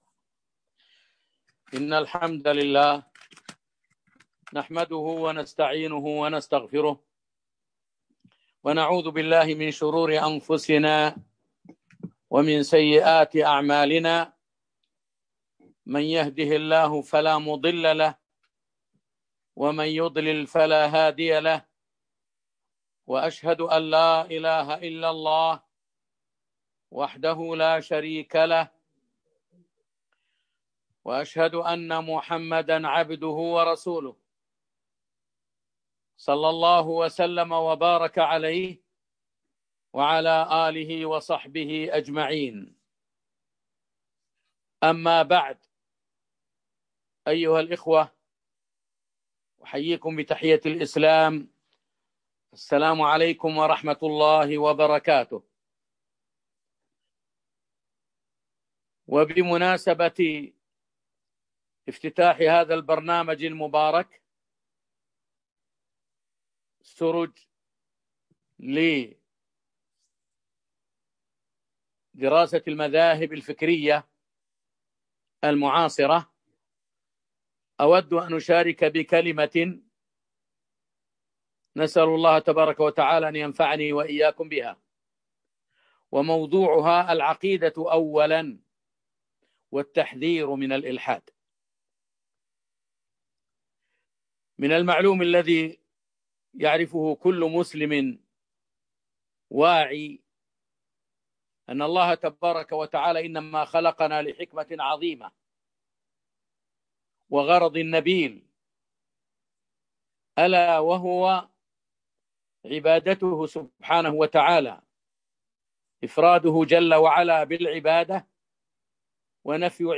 محاضرة - العقيدة أولا وخطر الإلحاد